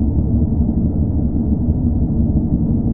liftamb.ogg